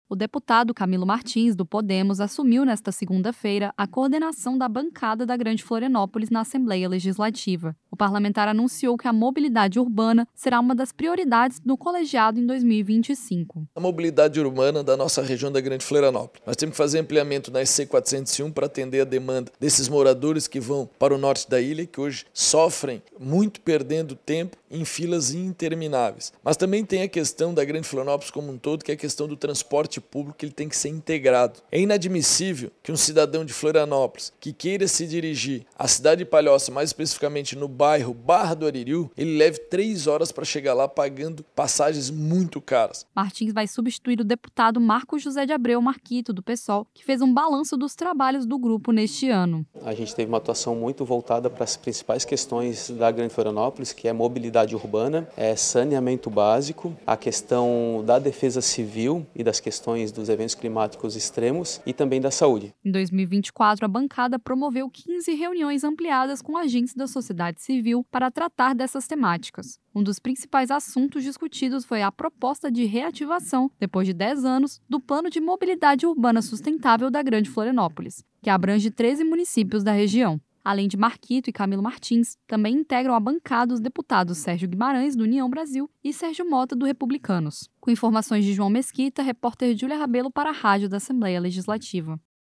Entrevistas com:
- deputado Camilo Martins (Podemos), coordenador da Bancada da Grande Florianópolis;
- deputado Marcos José de Abreu - Marquito (Psol), ex-coordenado da Bancada da Grande Florianópolis.